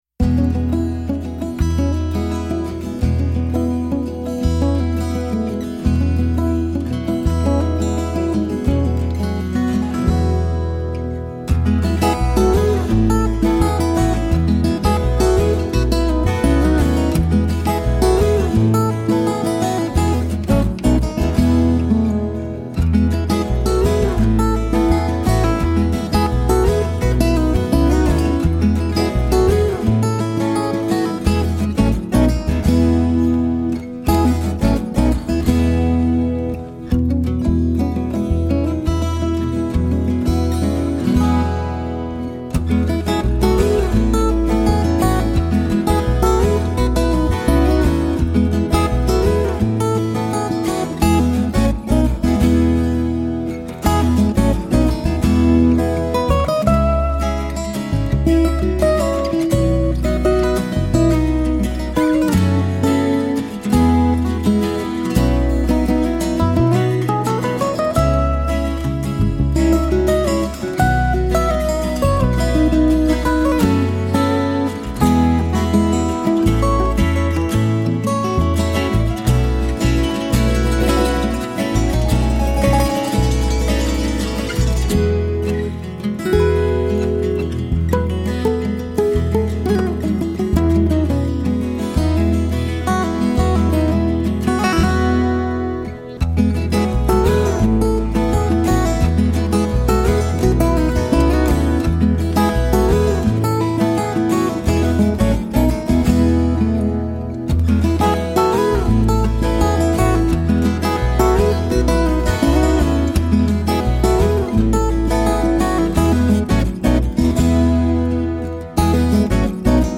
acoustic duo